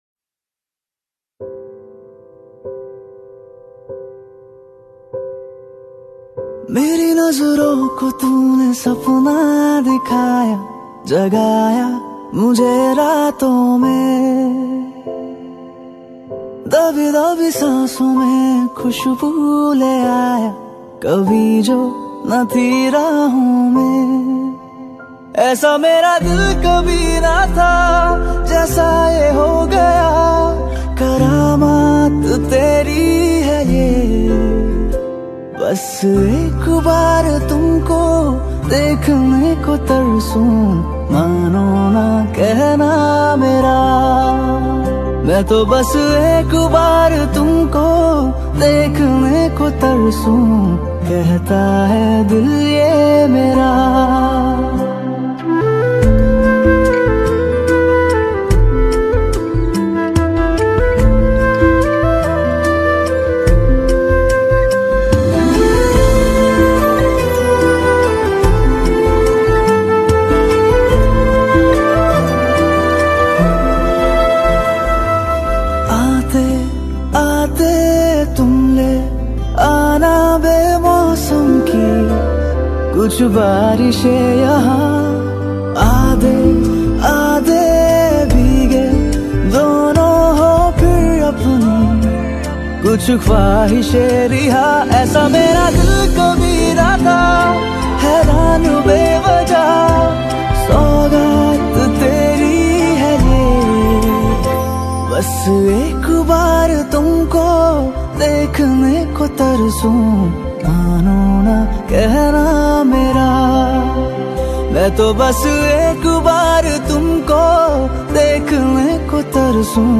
Pop Songs
Indian Pop